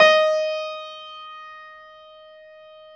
53d-pno15-D3.wav